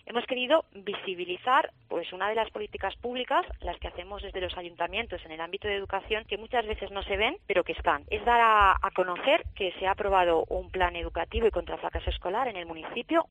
Eva Martínez, alcaldesa de Vallirana